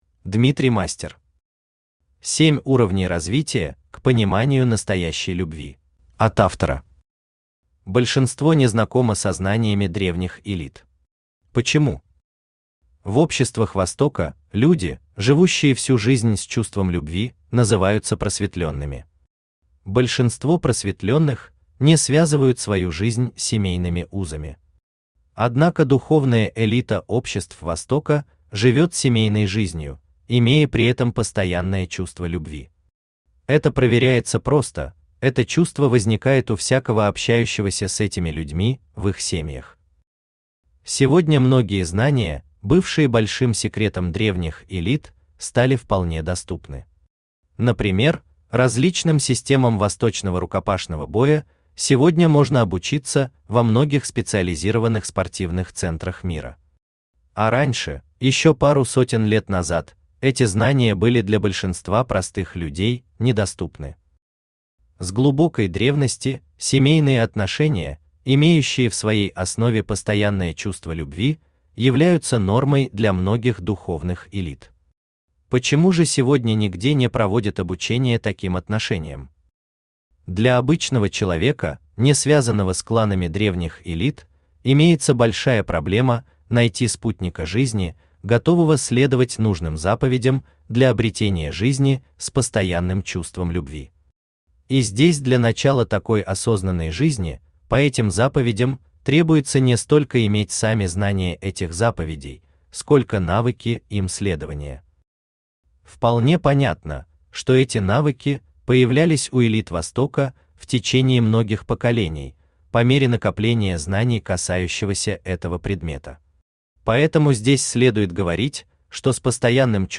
Аудиокнига 7 уровней развития к пониманию настоящей Любви!
Автор Дмитрий Мастер Читает аудиокнигу Авточтец ЛитРес.